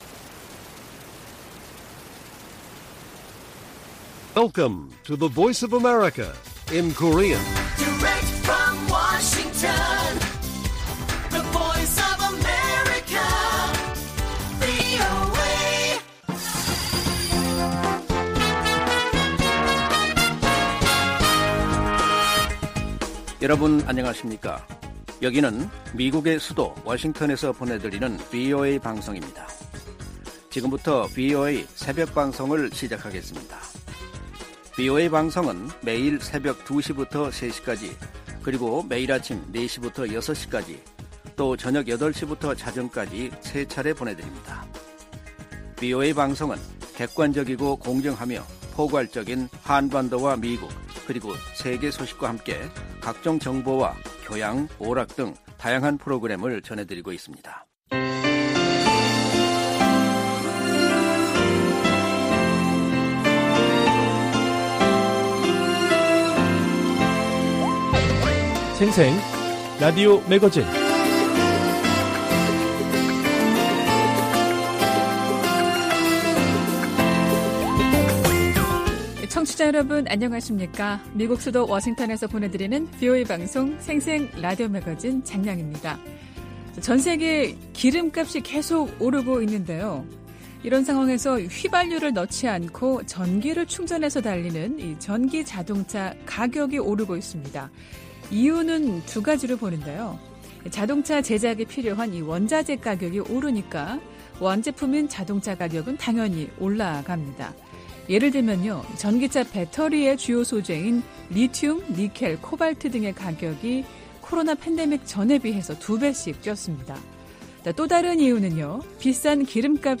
VOA 한국어 방송의 월요일 새벽 방송입니다. 한반도 시간 오전 2:00 부터 3:00 까지 방송됩니다.